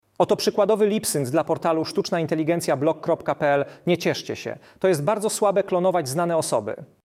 Weźmiemy przykładowe nagranie z internetu i dorobimy do niego sklonowany głos marszałka Szymona Hołowni.
Oto sklonowany głos: